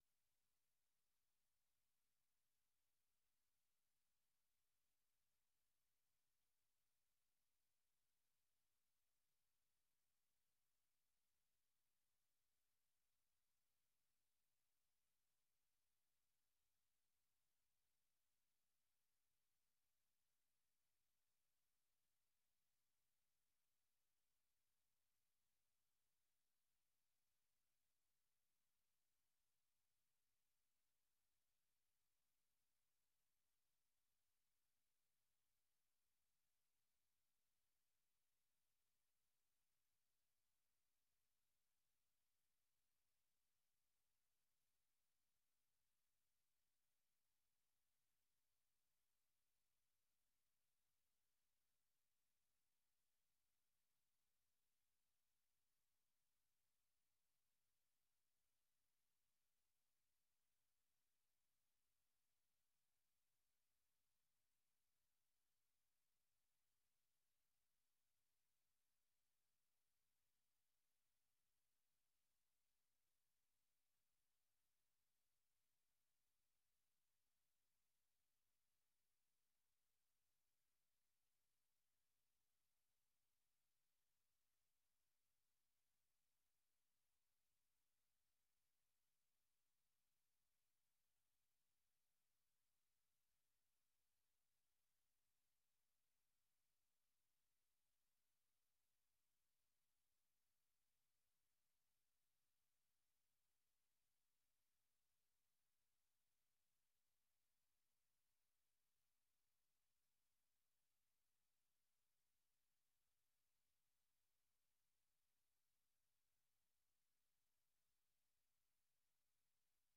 Nûçeyên Cîhanê 1